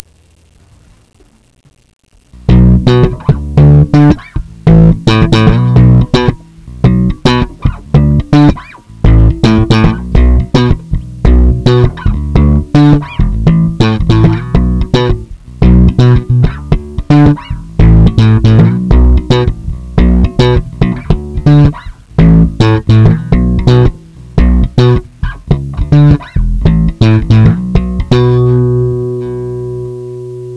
ギターのクラスを取り始めてからできた曲で